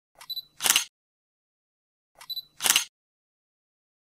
دانلود آهنگ عکس گرفتن دوربین عکاسی 2 از افکت صوتی اشیاء
جلوه های صوتی
دانلود صدای عکس گرفتن دوربین عکاسی 2 از ساعد نیوز با لینک مستقیم و کیفیت بالا